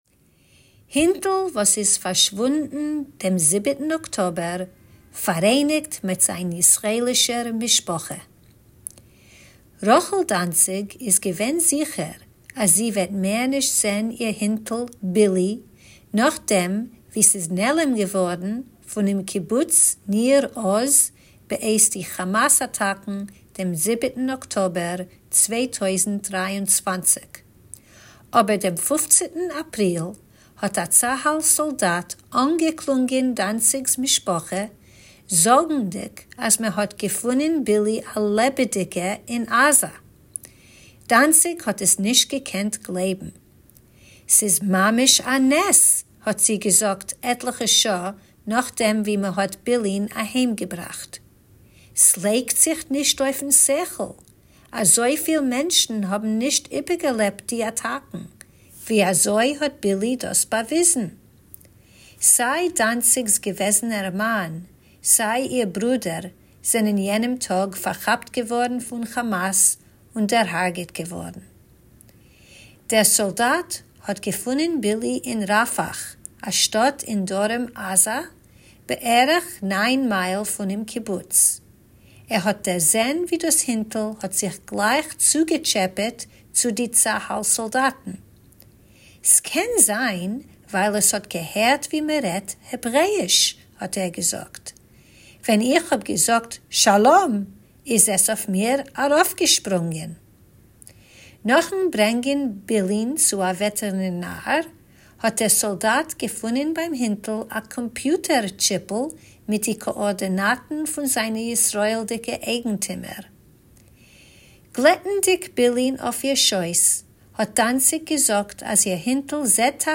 Tidbits is a bi-weekly feature of easy news briefs in Yiddish that you can listen to or read, or both!